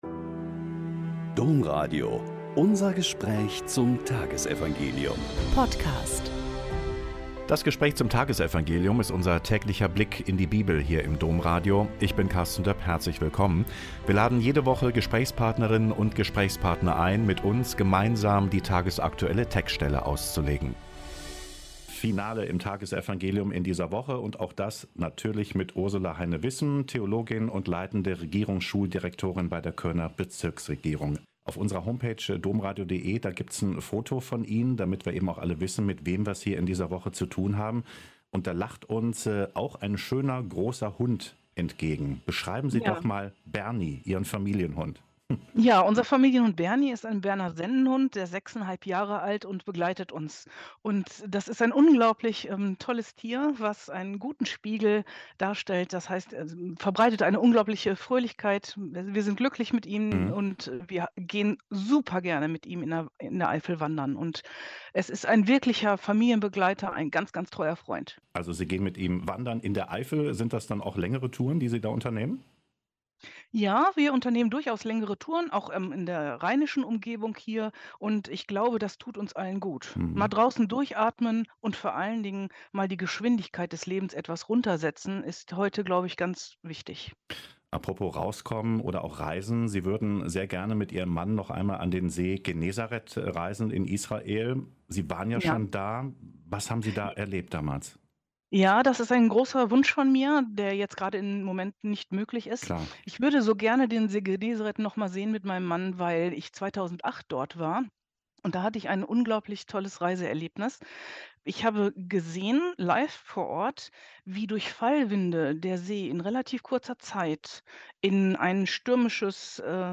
Lk 10,17-24 - Gespräch